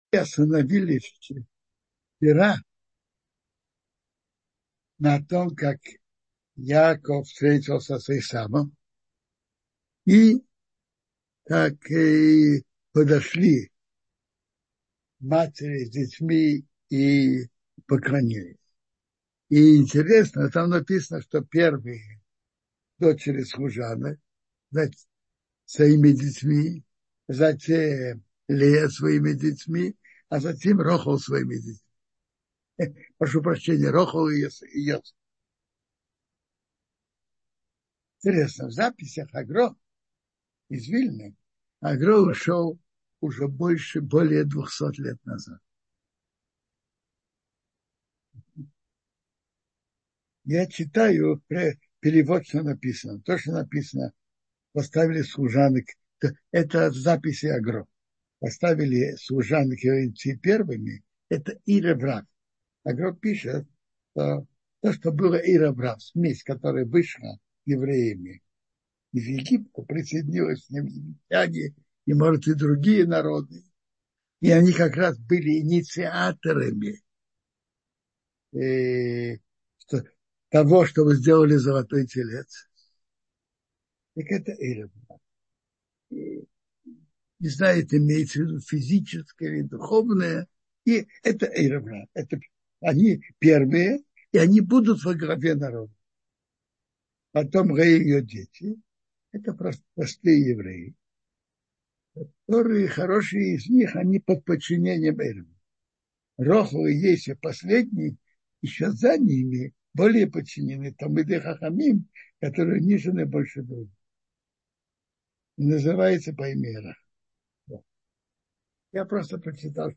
Жизнь Праотца Яакова — слушать лекции раввинов онлайн | Еврейские аудиоуроки по теме «Недельная глава» на Толдот.ру